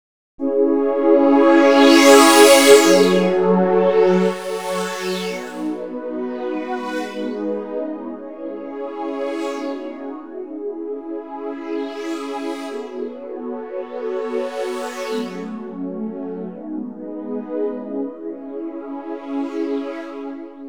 Brass Pads 120 bpm.wav